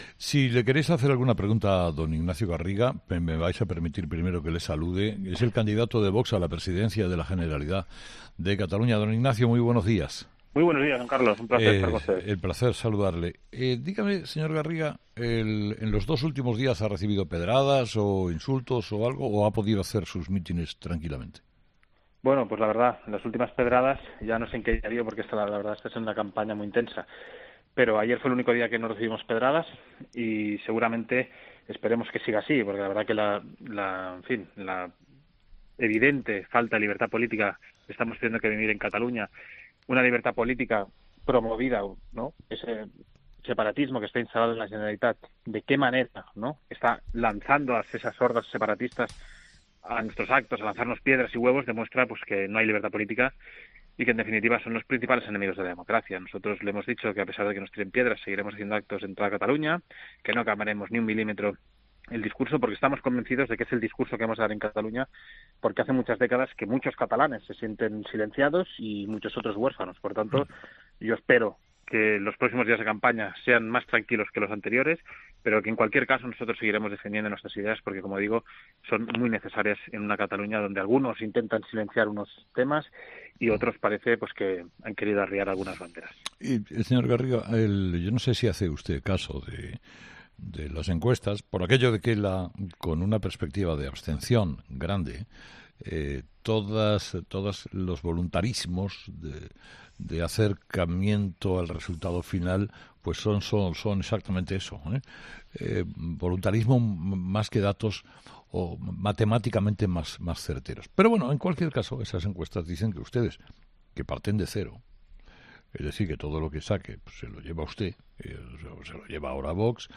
Ignacio Garriga , candidato de Vox a la presidencia de la Generalitat, ha hablado en ‘Herrera en COPE’